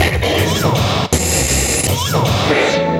80BPM RAD9-L.wav